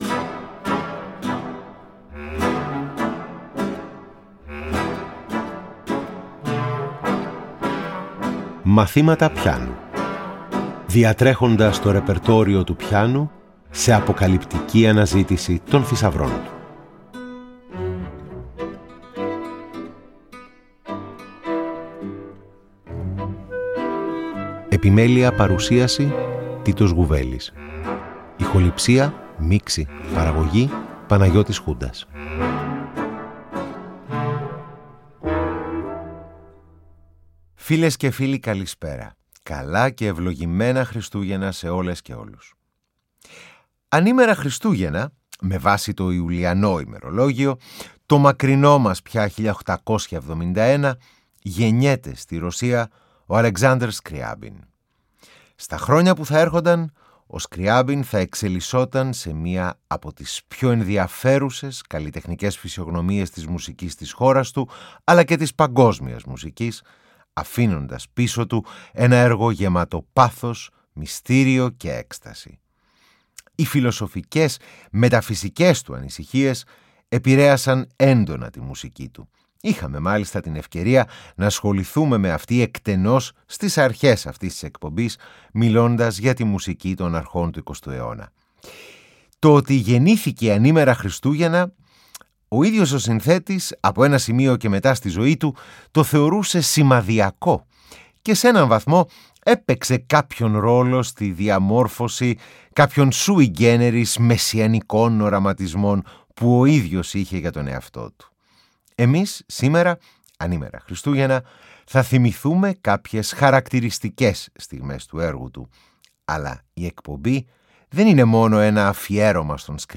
Η εκπομπή, ορμώμενη από το γεγονός ότι ο Ρώσος συνθέτης Alexander Scriabin γεννήθηκε (με βάση το Ιουλιανό ημερολόγιο) ανήμερα Χριστούγεννα του 1871, είναι αφιερωμένη αποκλειστικά σε πιανιστικά έργα του.
Εγχείρημα το οποίο ενισχύει η ενίοτε ζωντανή ερμηνεία χαρακτηριστικών αποσπασμάτων κατά τη διάρκεια της εκπομπής.